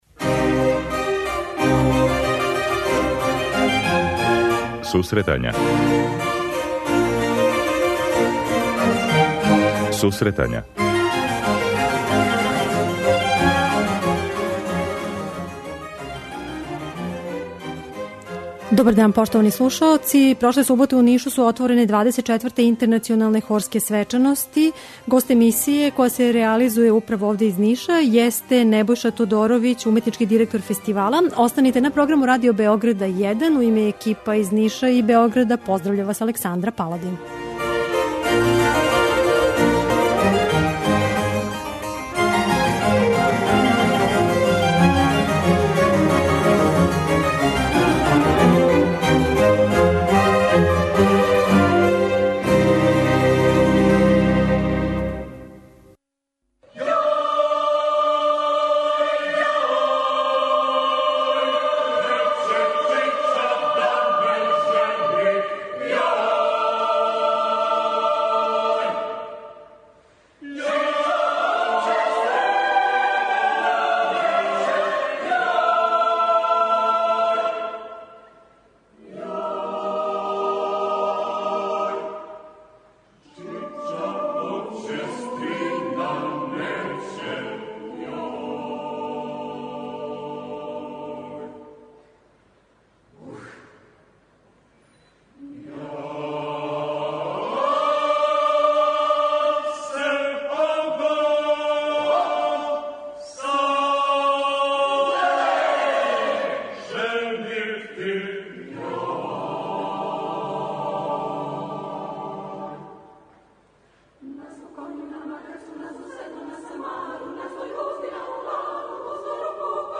Емисија се реализује из Ниша, где се одржава фестивал 24. Интернационалне хорске свечаности.